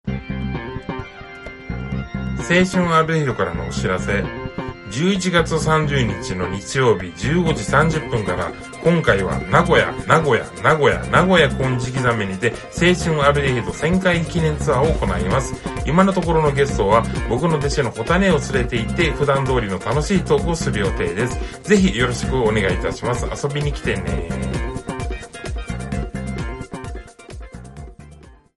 11/30名古屋イベントやります！CM音声！